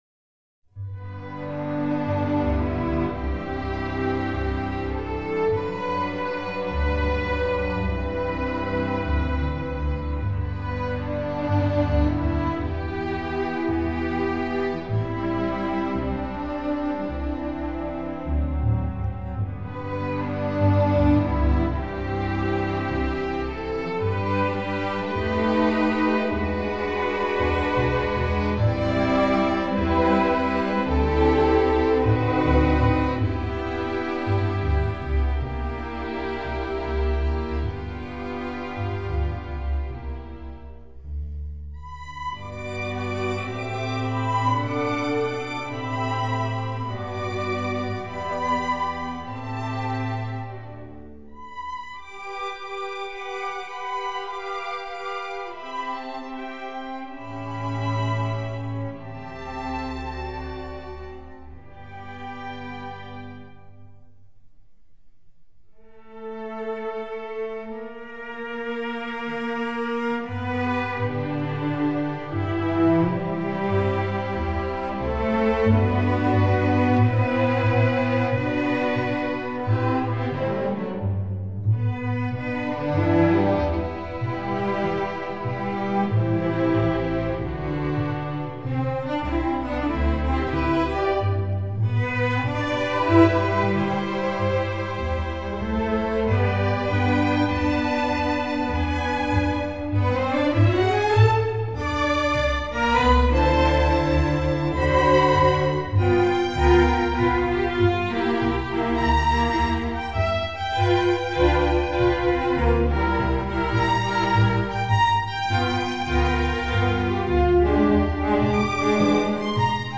Voicing: String Orc